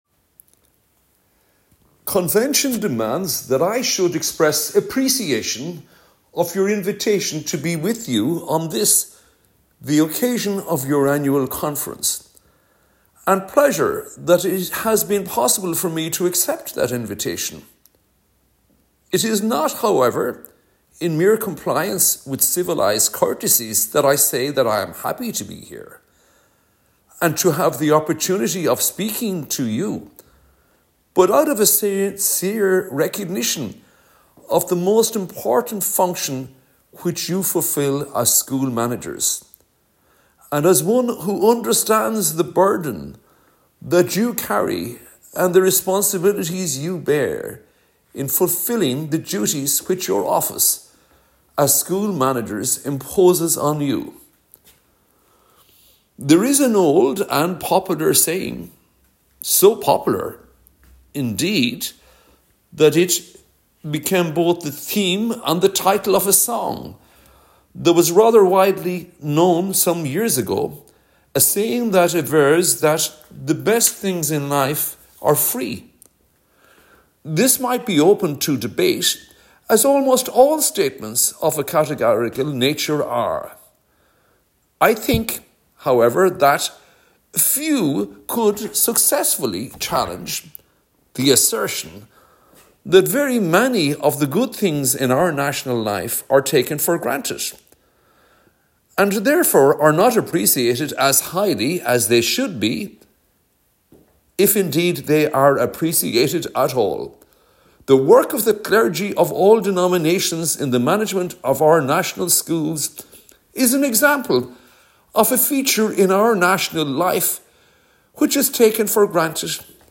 Minister Richard Burke’s address to the 1974 CPSMA AGM regarding the establishment of new Boards of Management